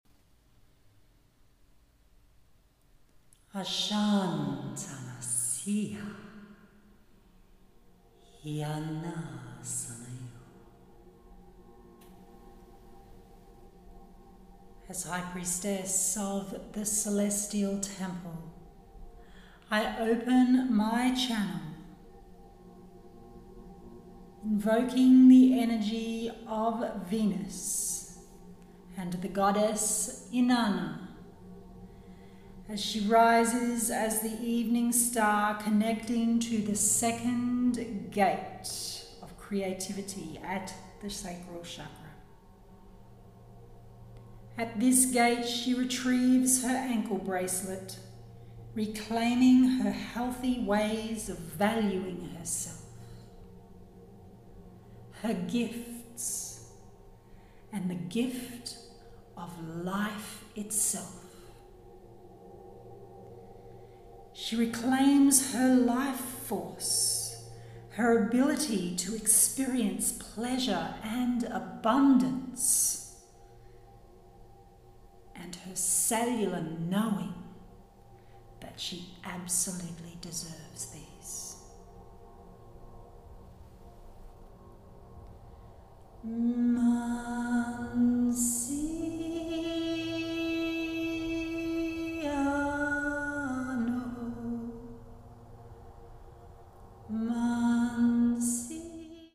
Here is a SNEAK PEEK of this AMAZING MEDITATION/TRANSMISSION – sharing the first 1.39 minutes of the 8minute Celestial Resonance Meditation Journey, you will need to purchase the mp3 audio file to experience the full journey.